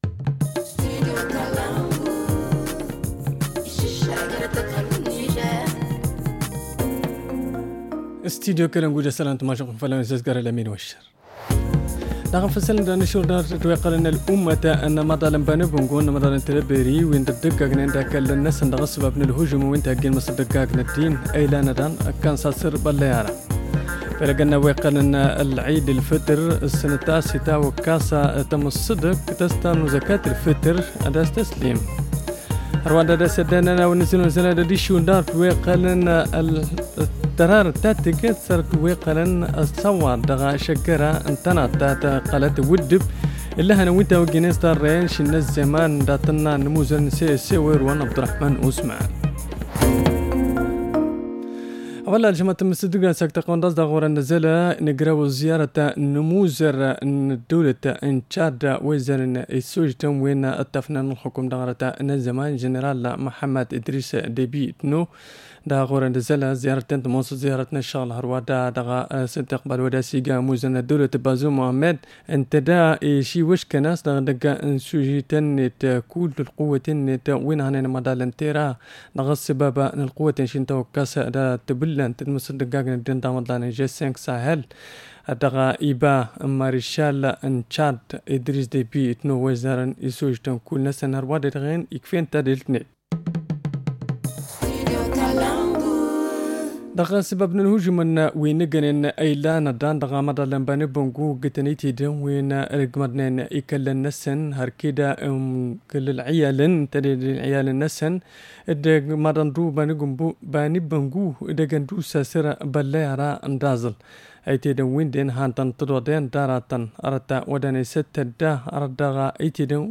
Le journal du 10 mai 2021 - Studio Kalangou - Au rythme du Niger